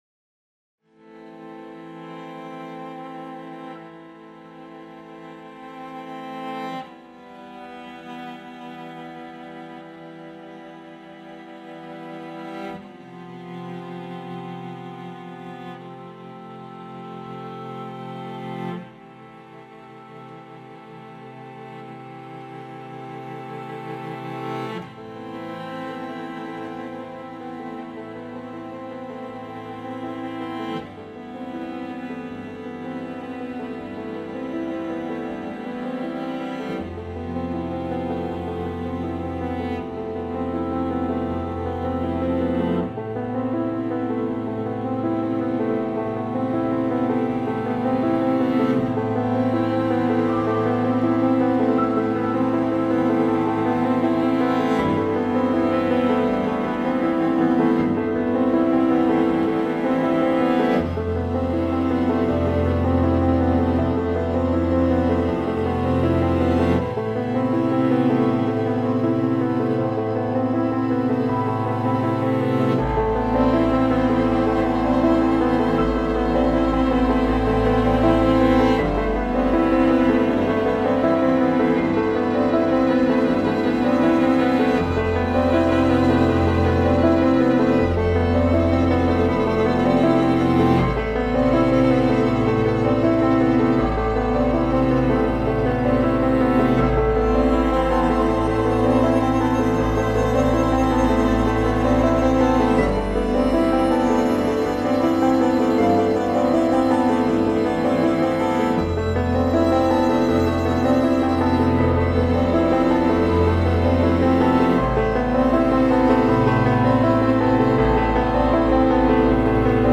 Classical Crossover
پیانو
موسیقی بی کلام ابری و بارانی موسیقی بی کلام ویولن